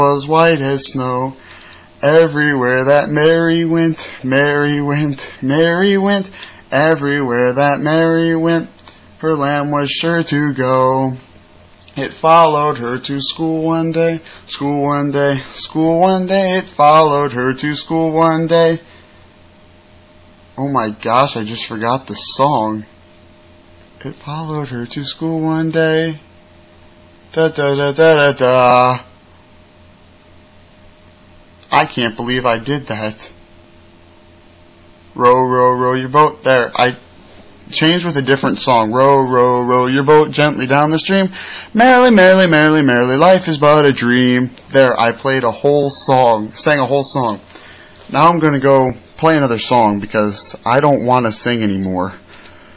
some of the DJ's singing on a live broadcast.